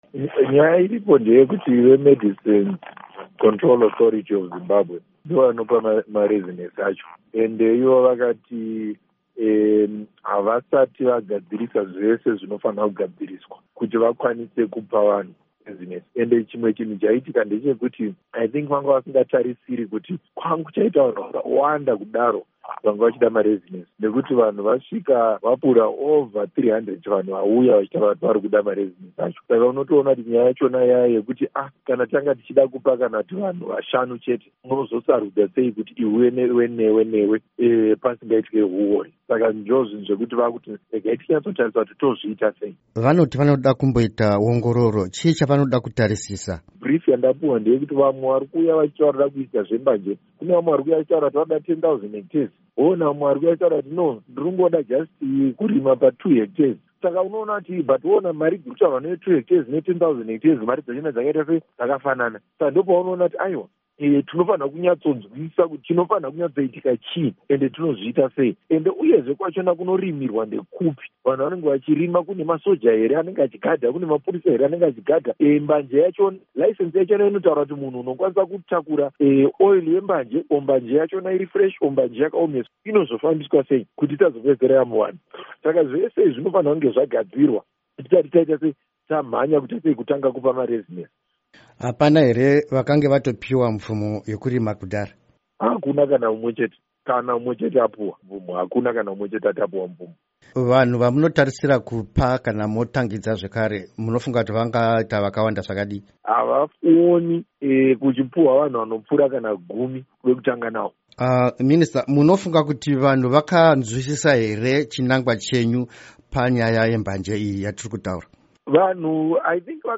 Hurukuro naVaTerrance Mukupe